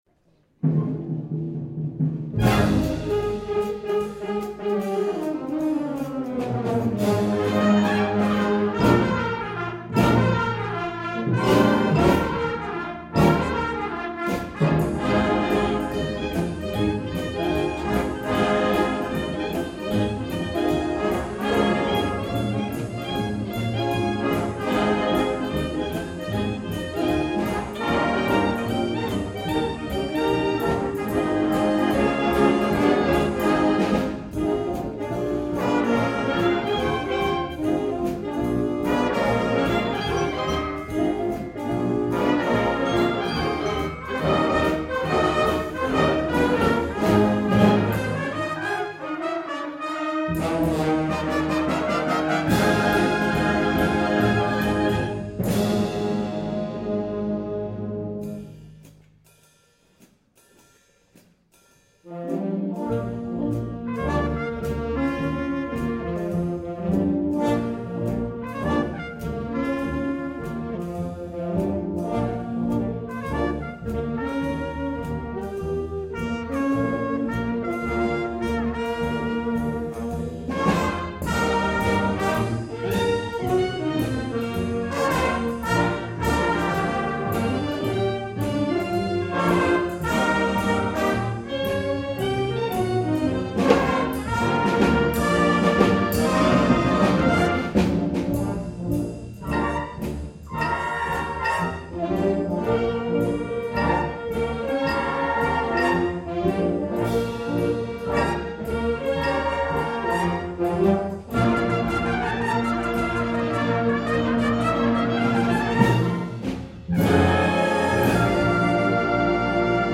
2013 Winter Concert